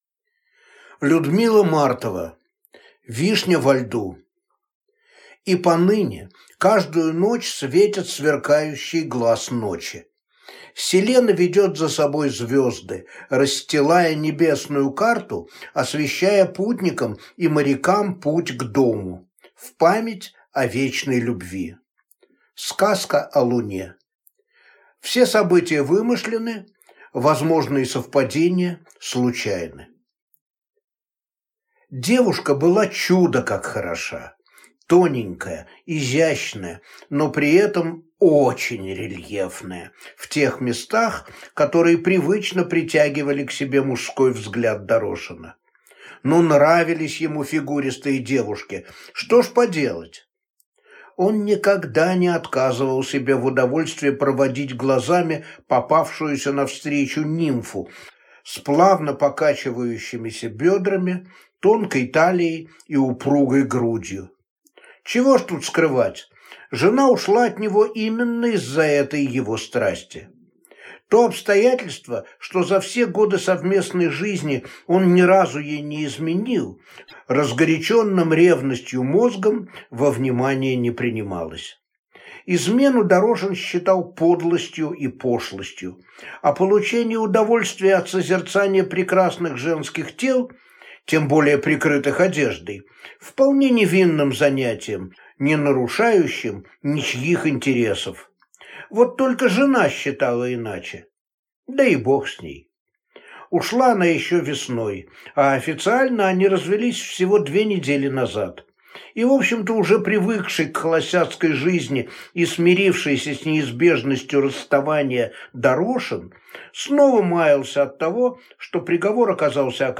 Аудиокнига Вишня во льду | Библиотека аудиокниг